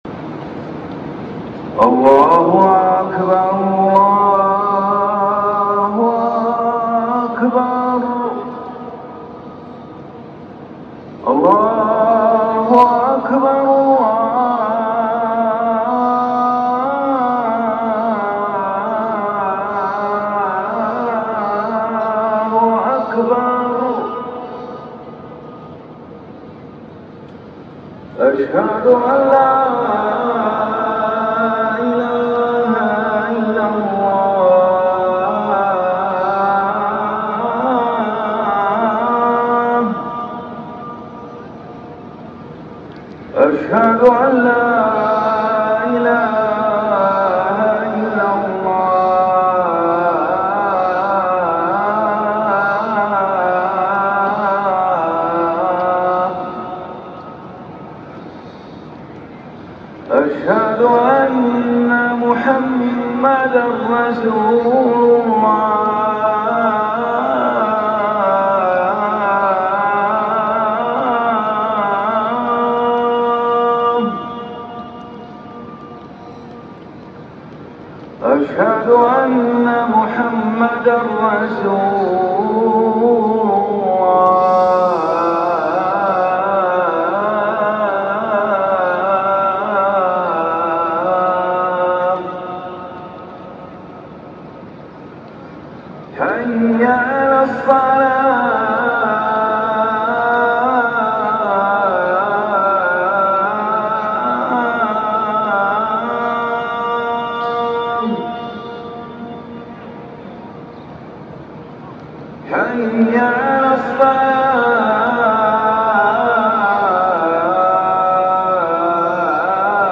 أذان الفجر الأول
الأذان الأول لصلاة الفجر